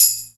Closed Hats
Hat (40).wav